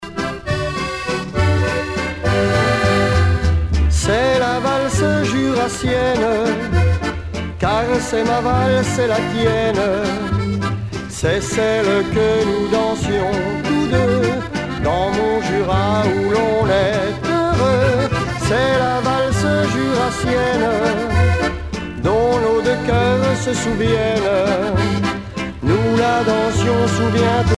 danse : valse
Genre strophique
Pièce musicale éditée